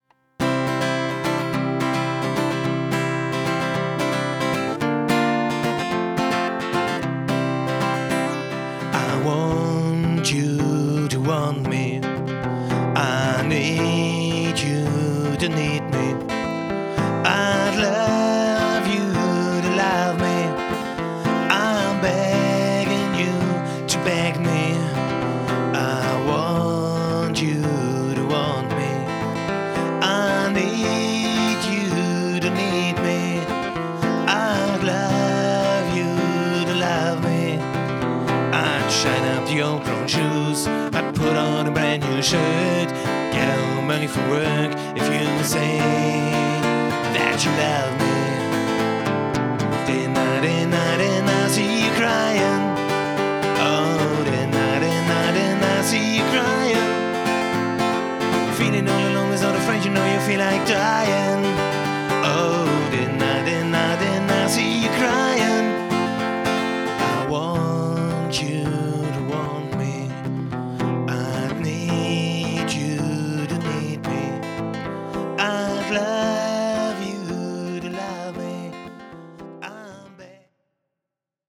Rock & Pop Cover